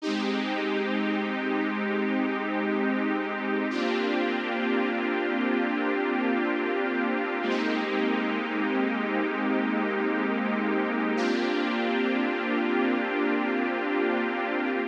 01 pad.wav